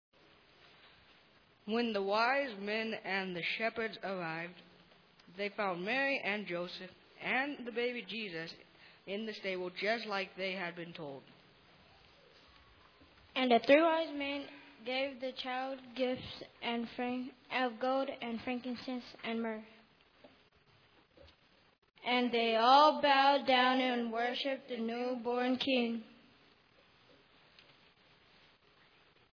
Burns Chancel Choir Cantata
Narrated by the Burns Youth